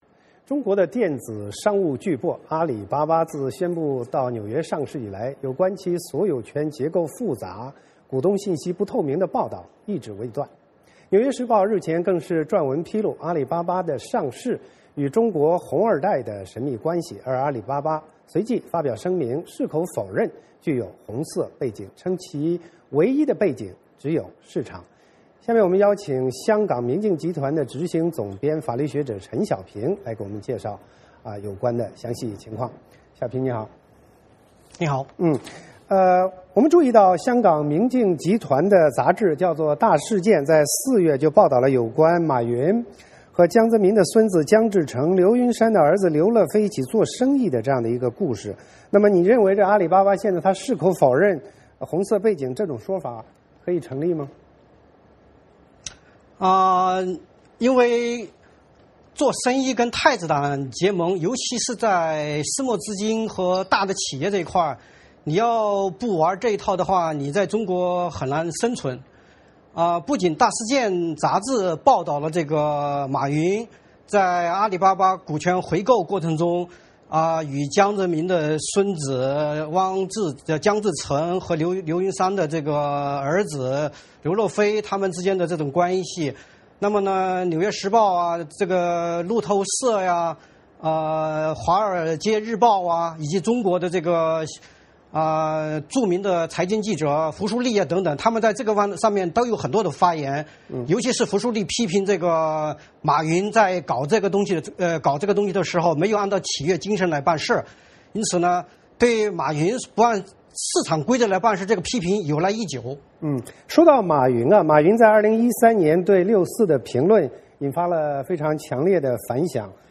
我们连线纽约